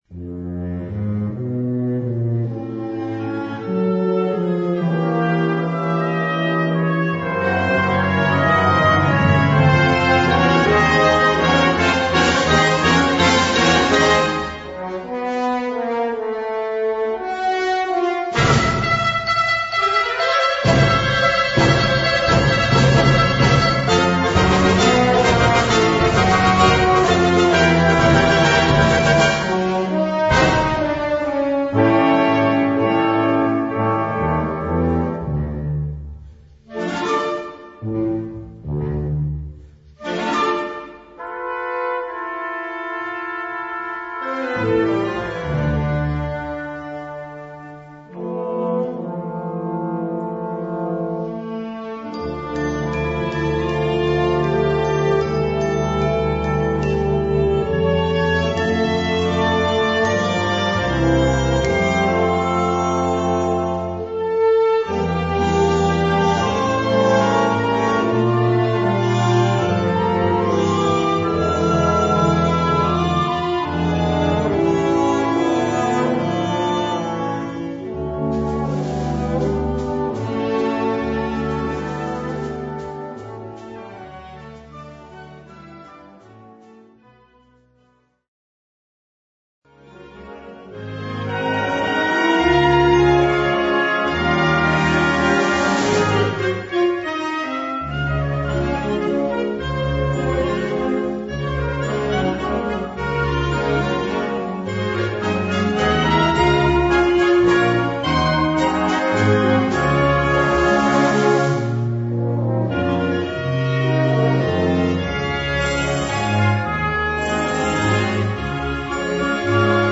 Subcategorie Suite
Bezetting Ha (harmonieorkest); / (oder); Fa (fanfare)